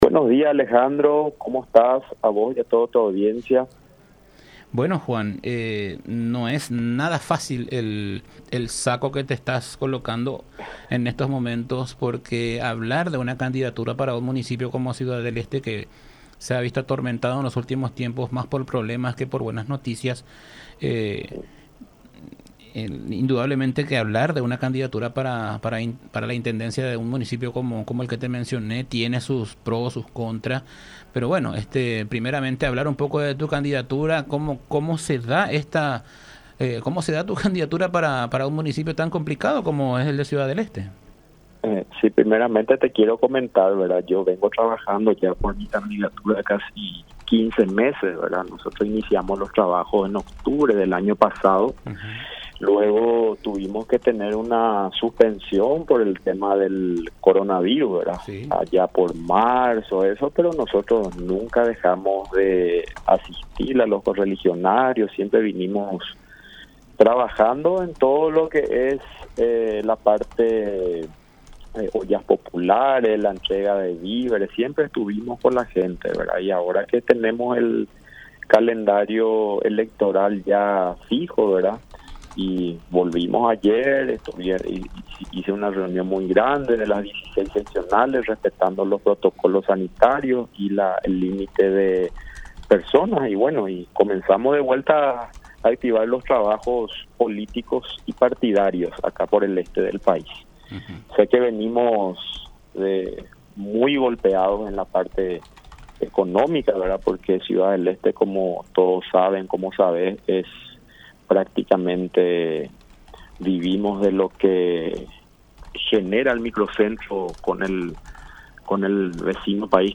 “No podemos abandonar al partido, yo tengo un compromiso con mi gente, con el pueblo, con el partido y venimos trabajando con todo para poder recuperar nuestro municipio verdad, porque como todos saben se perdió un par de años acá aún independiente y vamos a poner ahora toda la garra y vamos a tirar, como se dice, toda la carne al asador para volver hacer poder”, expresó en diálogo con La Unión R800 AM.